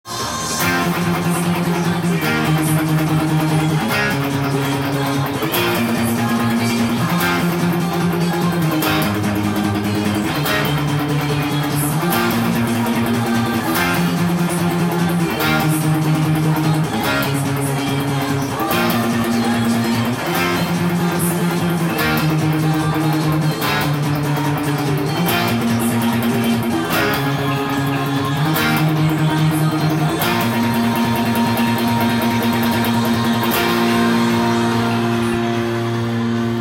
音源にあわせて譜面通りギターで弾いてみました